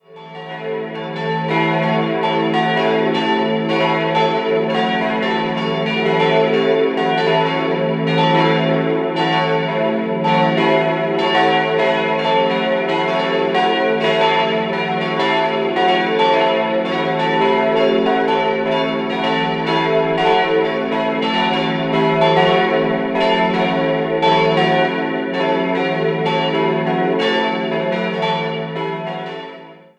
Im Inneren sind vor allem die großen Glasfenster sowie das umlaufende Lichtband raumprägend. 4-stimmiges Geläute: e'-fis'-a'-cis'' Die Glocken wurden 1964 von Rudolf Perner in Passau gegossen.